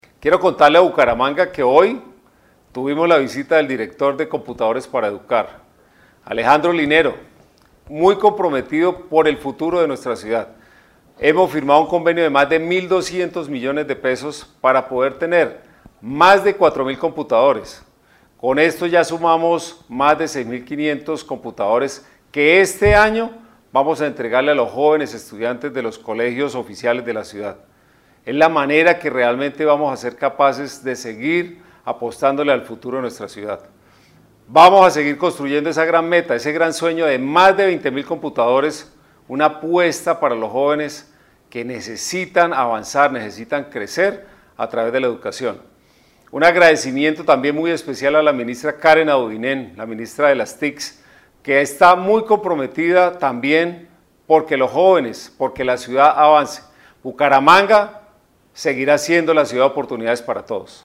Descargue audio: Juan Carlos Cárdenas, alcalde de Bucaramanga